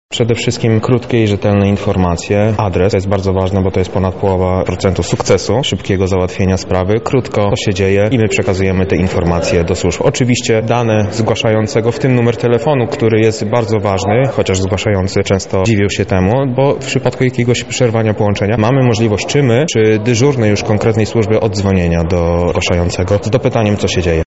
O tym, jak powinno wyglądać wzorowe zgłoszenie, które ułatwi jej wykonywanie, mówi operator numerów alarmowych